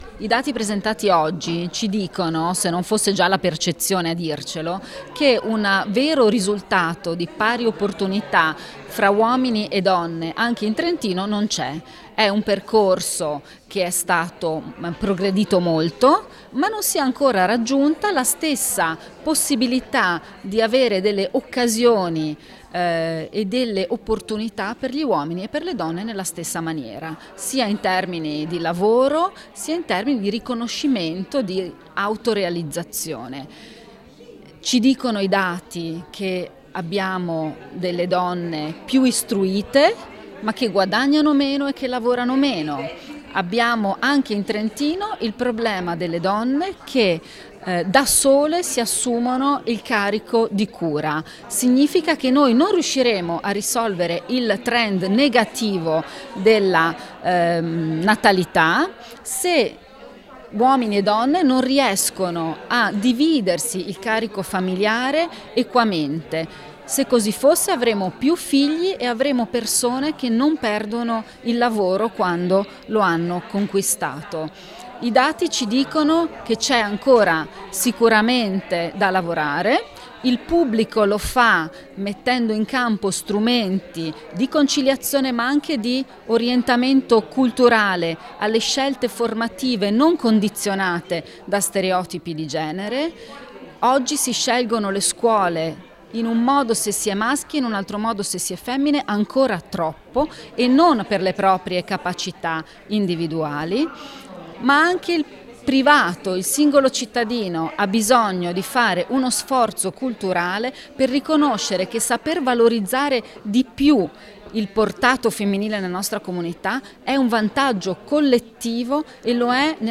Interv_Ass_Ferrari.mp3